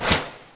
SharkEat.wav